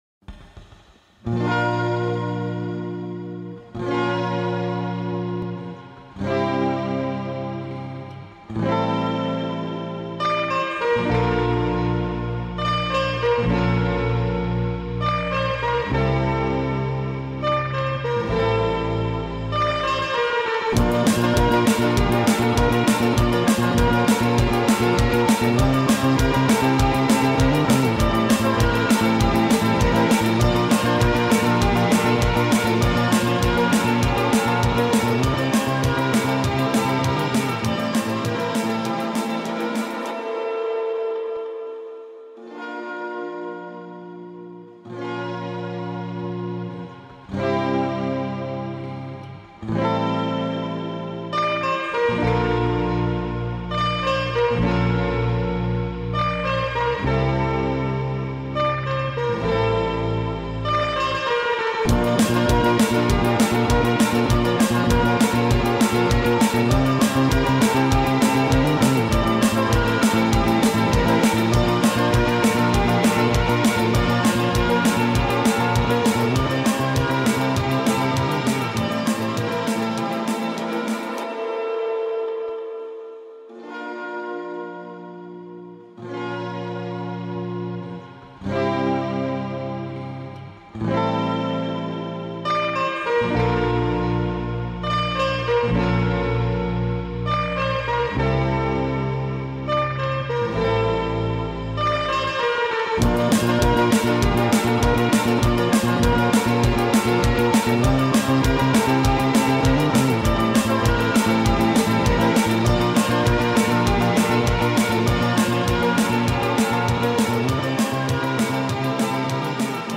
با ریتمی تند شده
غمگین